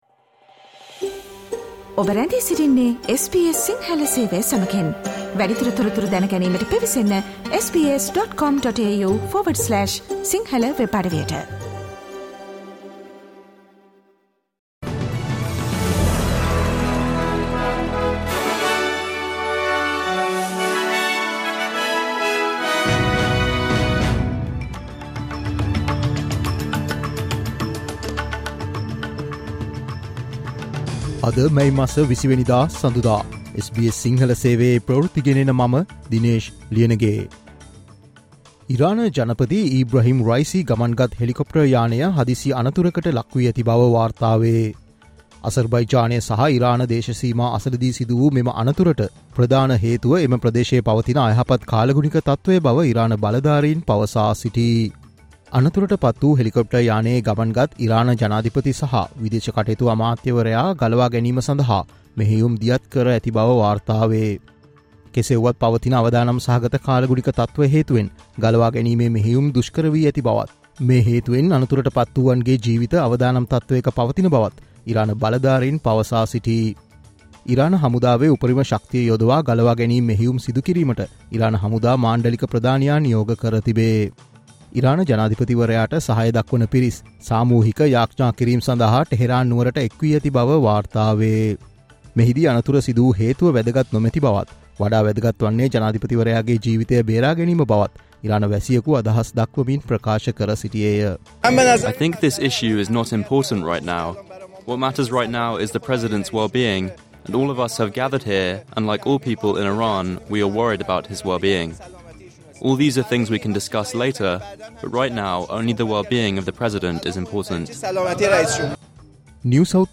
Australia news in Sinhala, foreign and sports news in brief - listen, today – Monday 20 May 2024 SBS Radio News